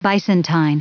Prononciation du mot bisontine en anglais (fichier audio)
Prononciation du mot : bisontine